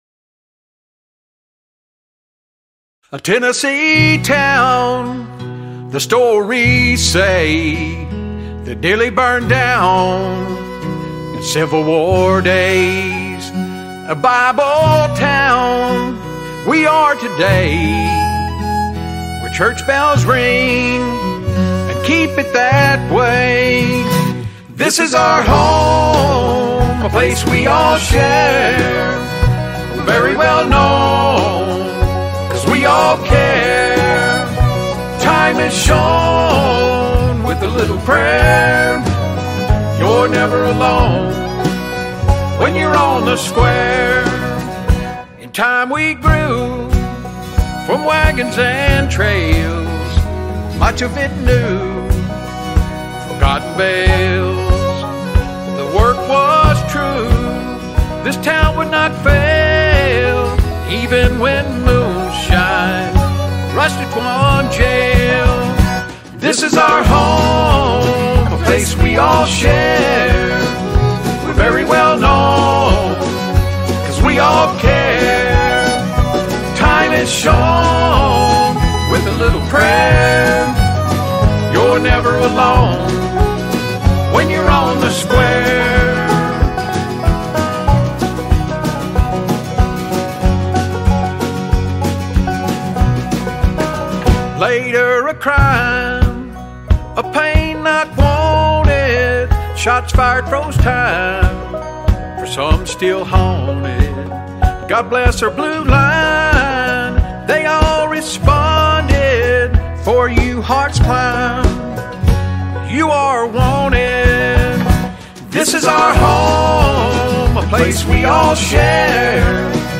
Violin
Banjo
Drummer
Harmony / Guitar
Guitar / Dobro
Lead Vocals